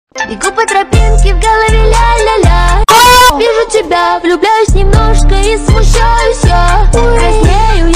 Звук из мема: Бегу по тропинке в голове ля-ля-ля Оооо! (стоны)